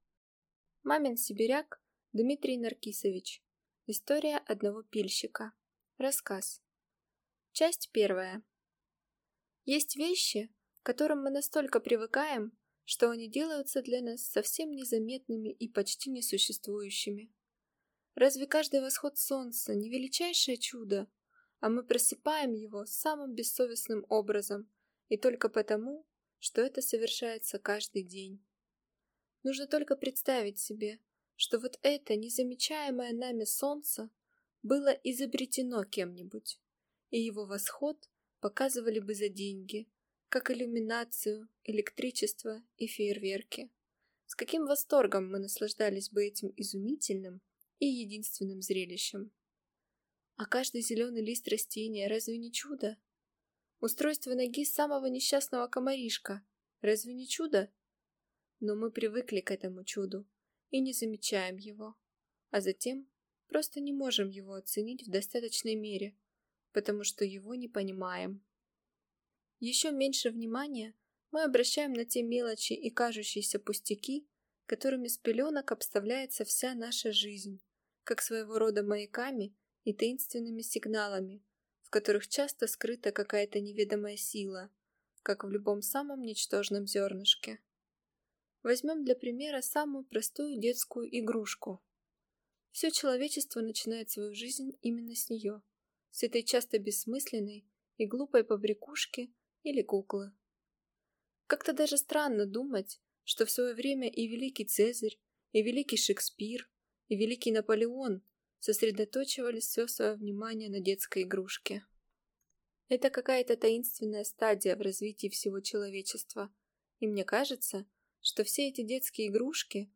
Аудиокнига История одного пильщика | Библиотека аудиокниг